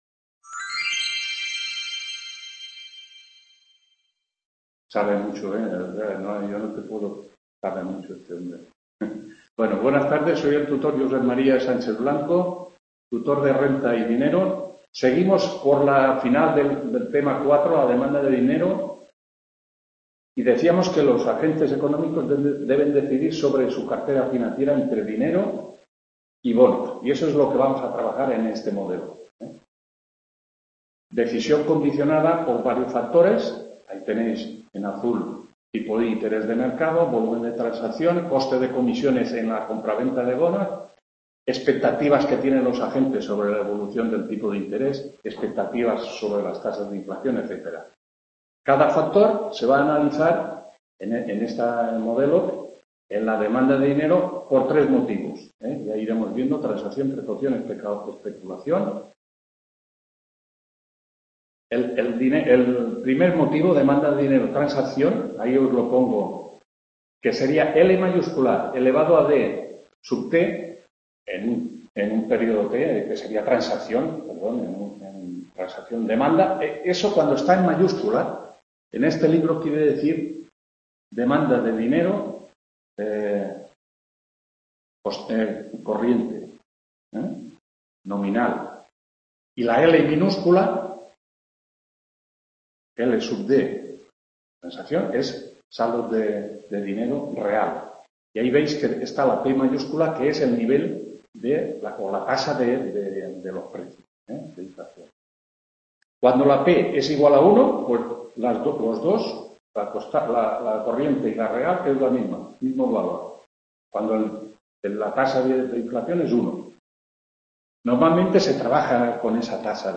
8ª TUTORÍA RENTA Y DINERO FIN TEMA 4,TEMA 5 (I)… | Repositorio Digital